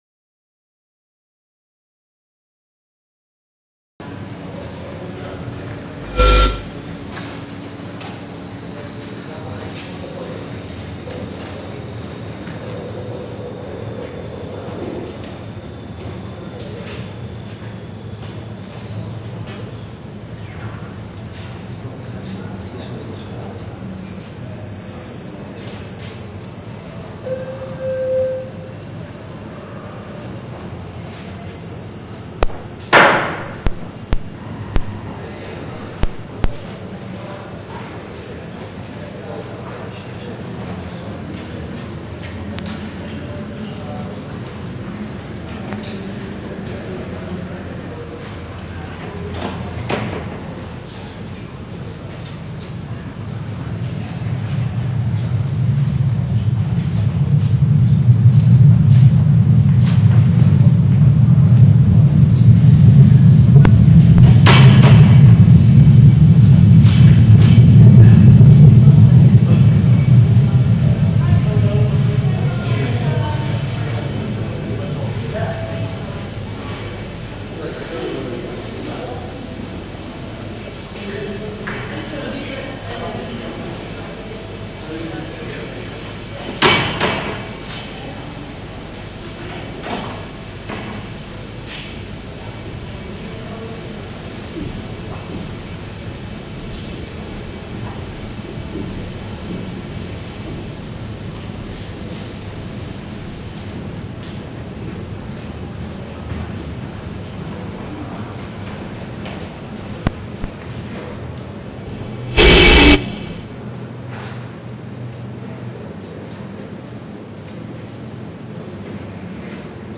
uses electronics and wind-up radios, running out of charge